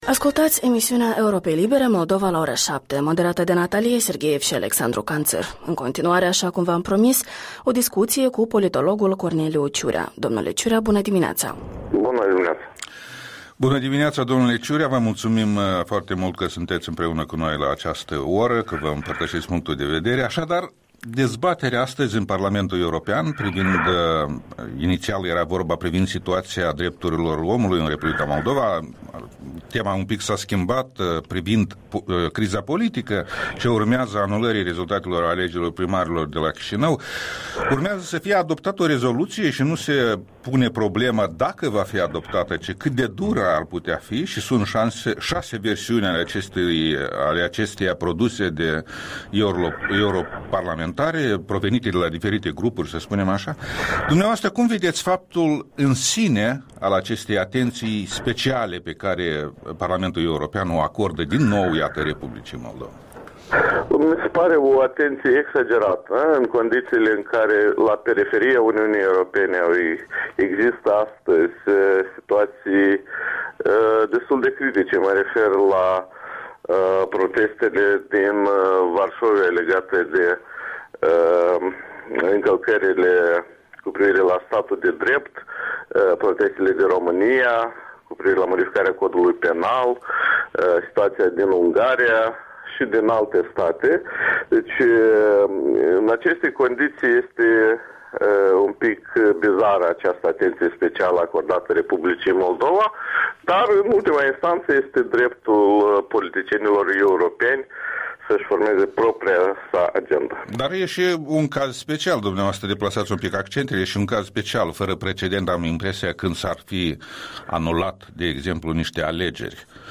Interviul dimineții despre dezbaterea situației din Moldova în Parlamentul European și consecințele așteptate.